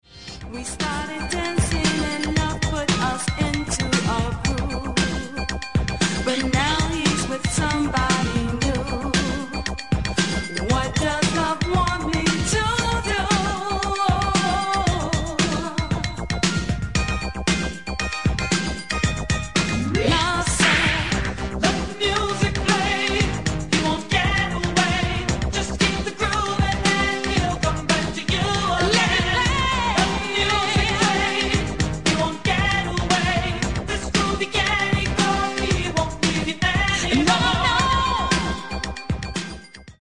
(Latin) orientated freestyle dance hit